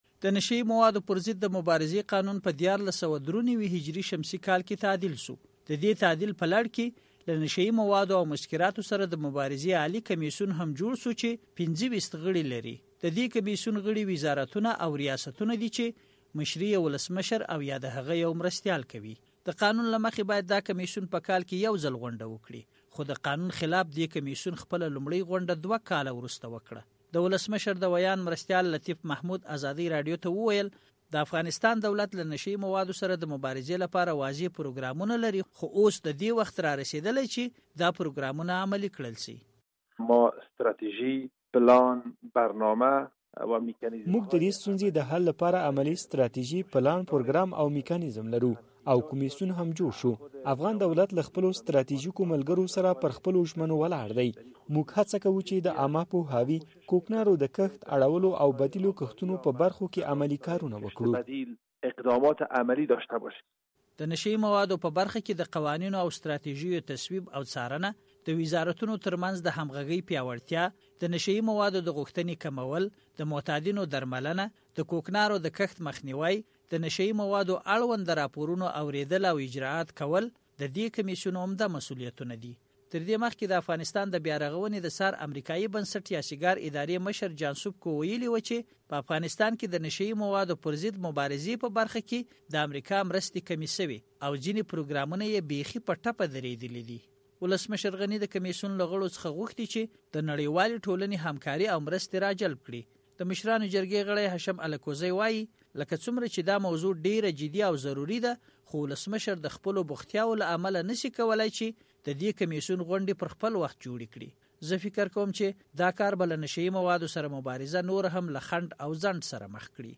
نشه يي موادو او مسکراتو سره د مبارزې کمېسیون راپور